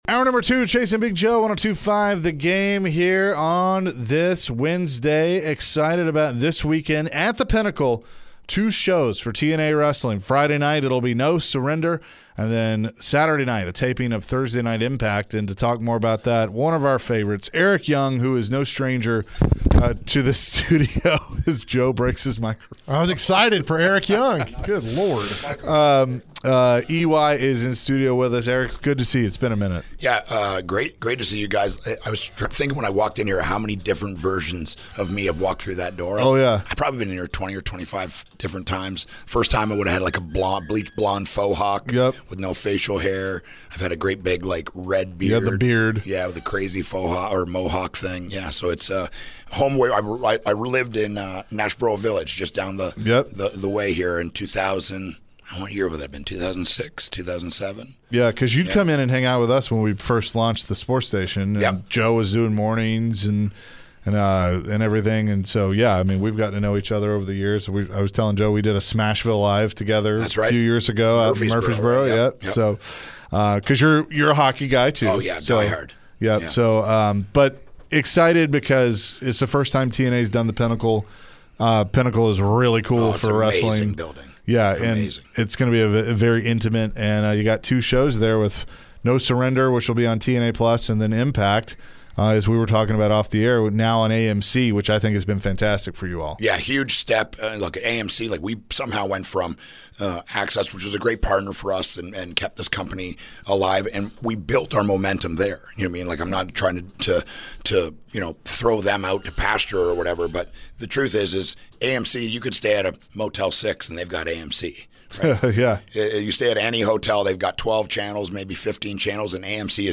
TNA Impact Wrestling is coming to town, and Eric Young was in the studio with the guys ahead of his match. Eric shared his wrestling career and what it's like being a top wrestler with TNA.